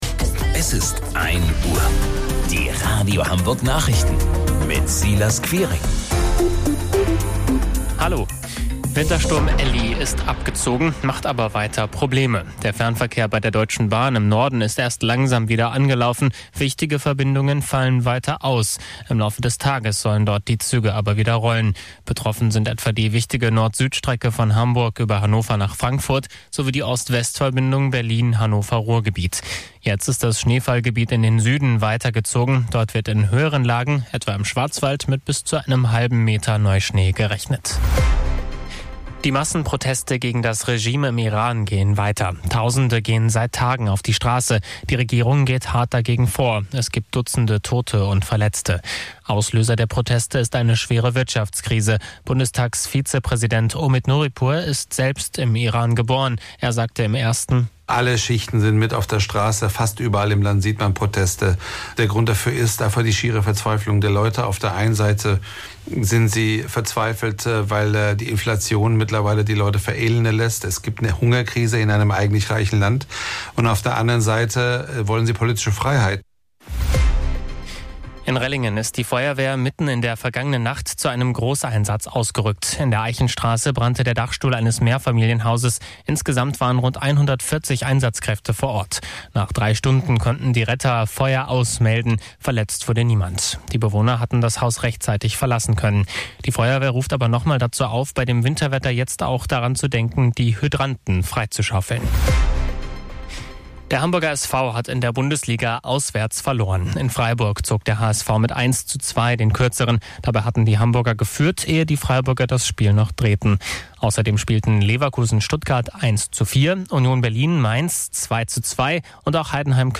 Radio Hamburg Nachrichten vom 11.01.2026 um 01 Uhr